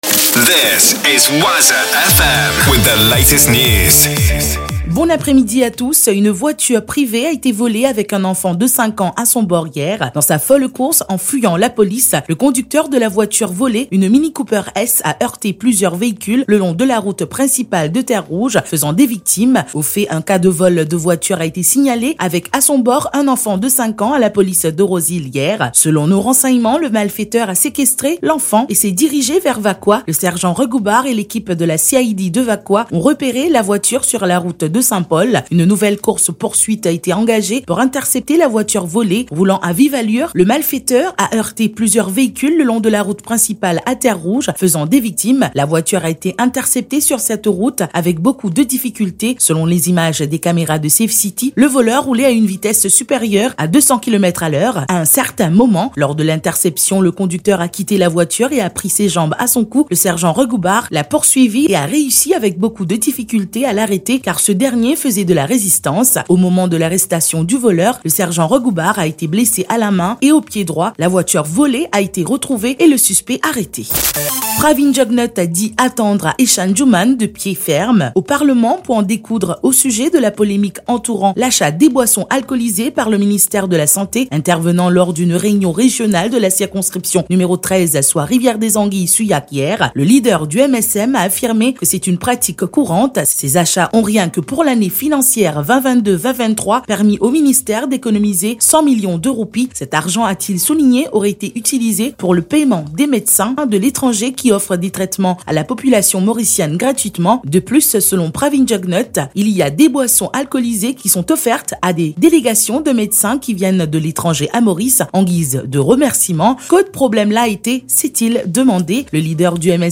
NEWS 15H - 24.11.23